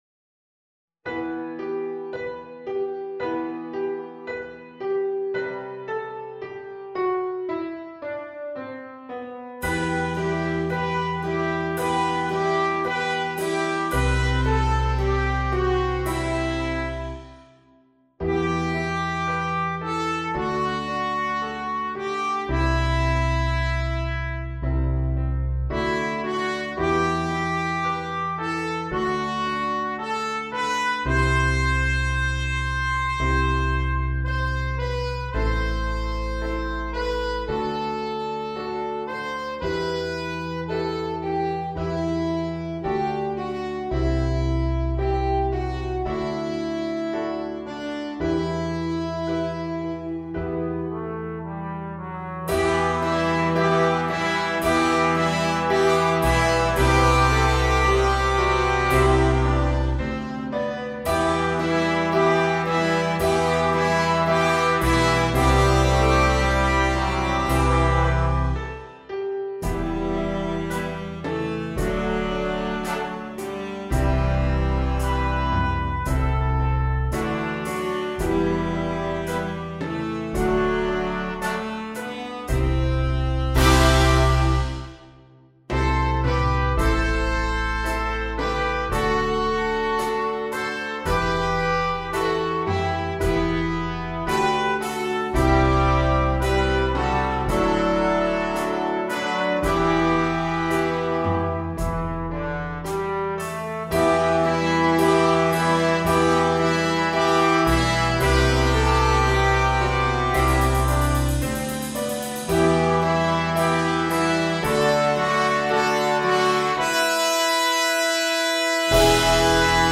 V-Part
Backing track